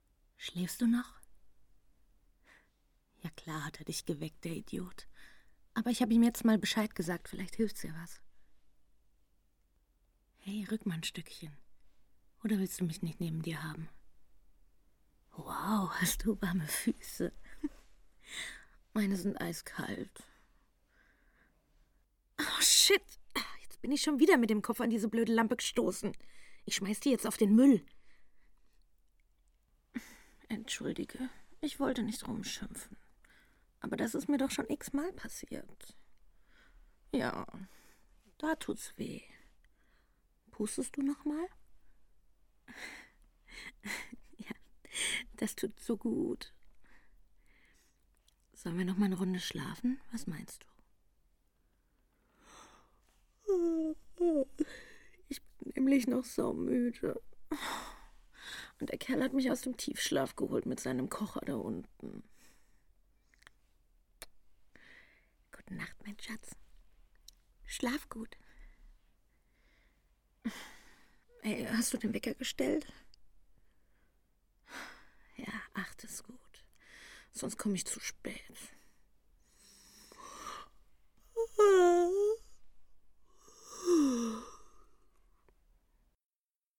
Kuscheln im Bett – schläfrig, kuschelig